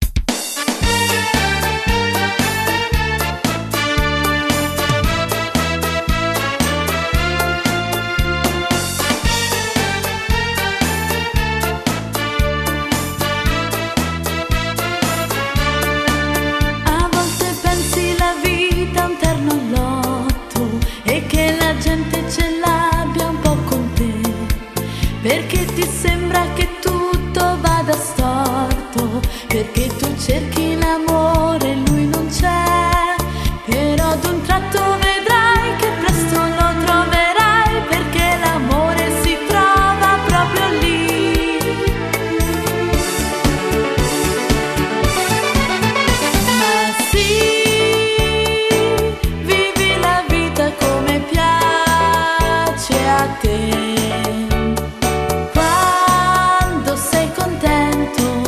Ritmo allegro